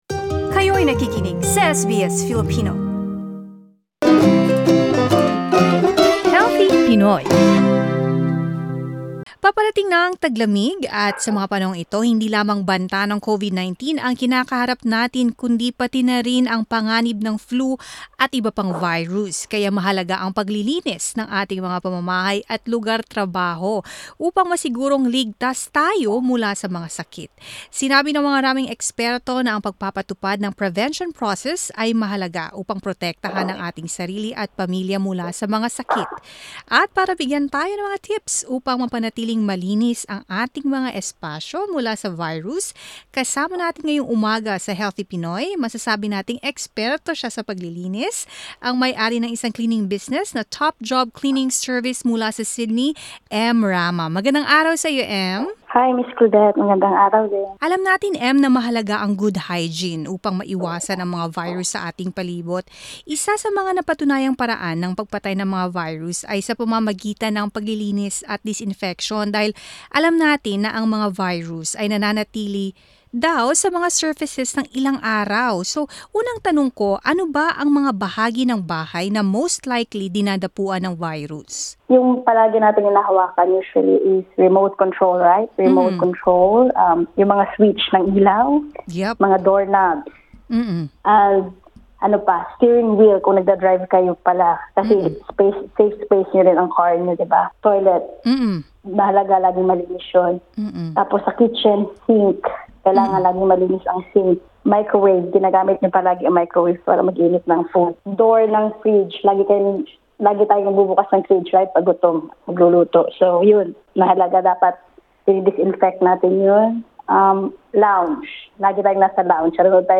Makinig sa audio LISTEN TO Paano panatilihing malinis ang bahay ngayong flu season SBS Filipino 08:28 Filipino Disclaimer: Ang mga impormasyon sa panayam na ito ay gabay lamang.